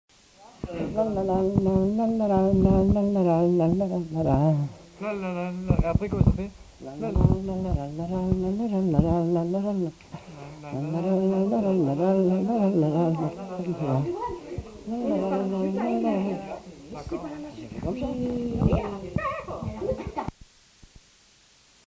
Aire culturelle : Grandes-Landes
Lieu : Luxey
Genre : chant
Type de voix : voix d'homme
Production du son : fredonné
Danse : scottish